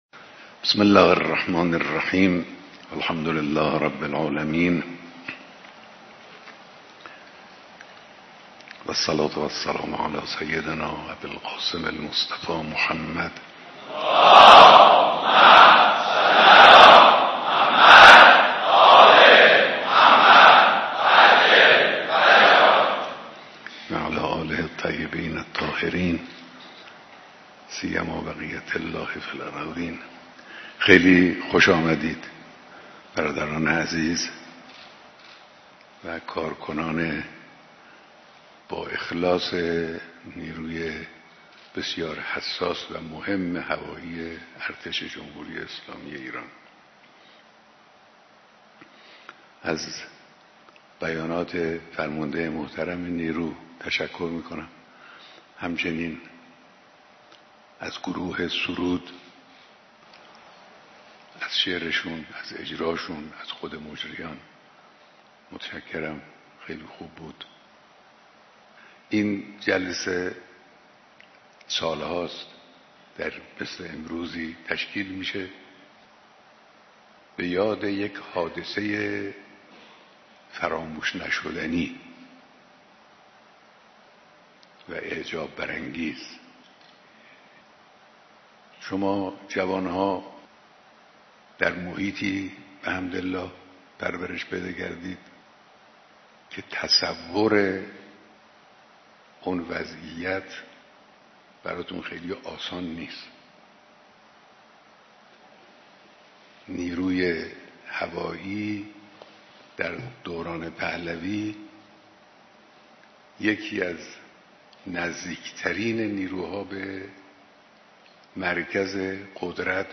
صوت کامل بیانات رهبر انقلاب در دیدار اعضای نیروی هوایی ارتش